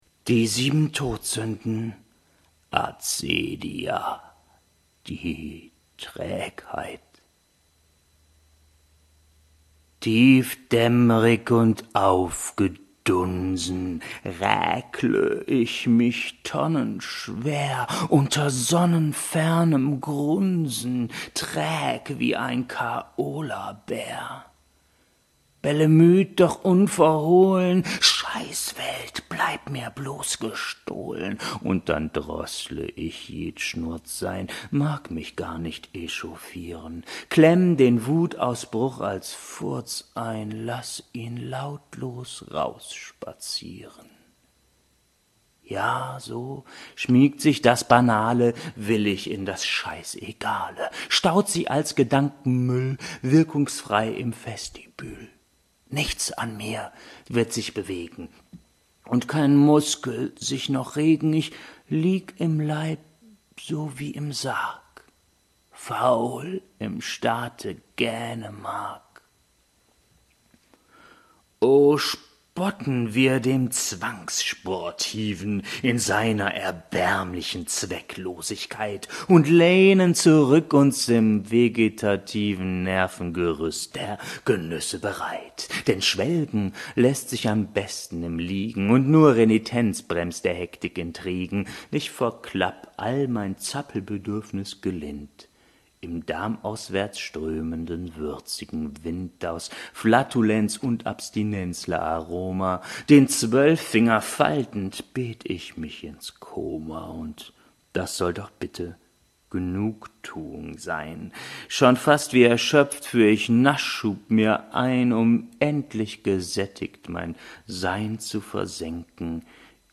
Die 7 Todsünden: Acedia. Die Trägheit (gekürzte Slamversion)